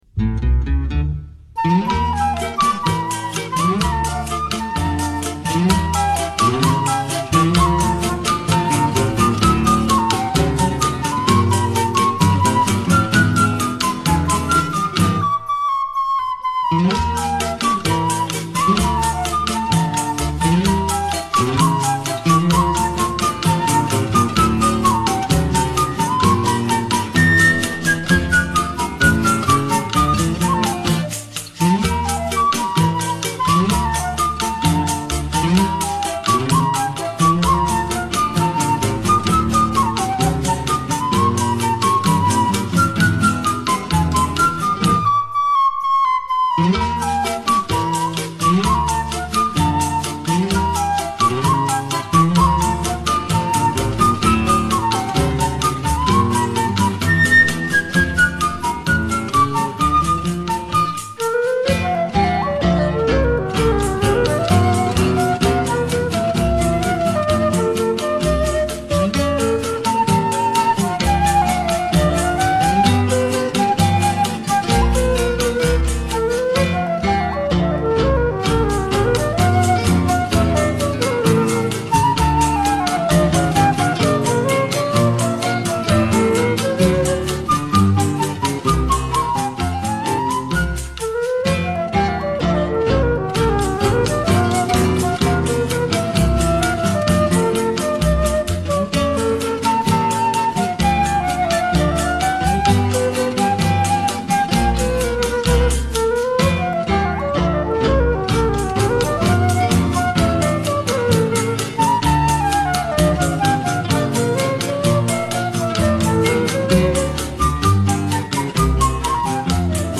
Chorinho